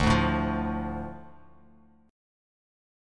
Dm Chord
Listen to Dm strummed